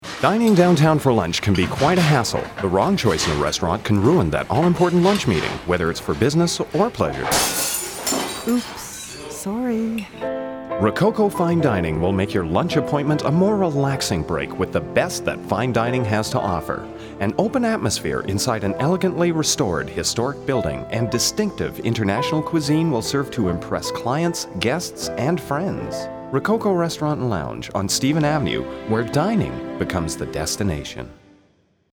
Rococo Commercial
All of the above audition examples were written and/or Voiced here.